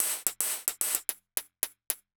Index of /musicradar/ultimate-hihat-samples/110bpm
UHH_ElectroHatA_110-02.wav